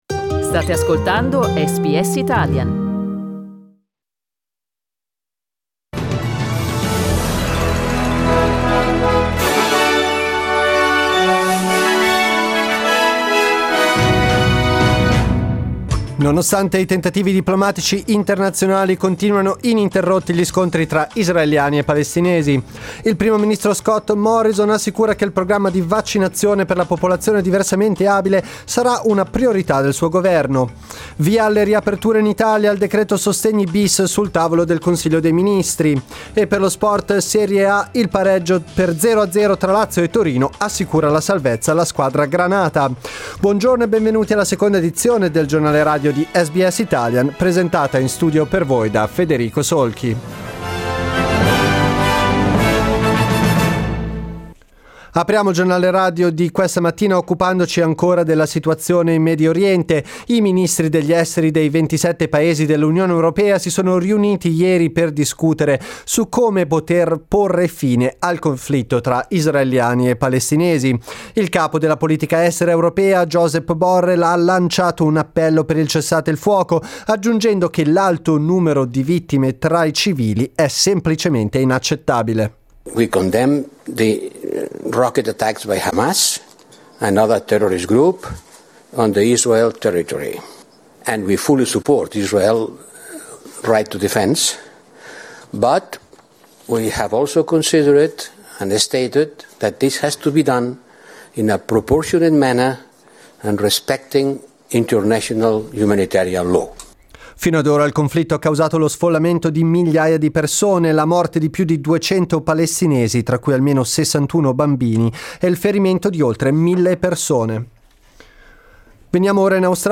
Giornale radio mercoledì 19 maggio
Il notiziario di SBS in italiano.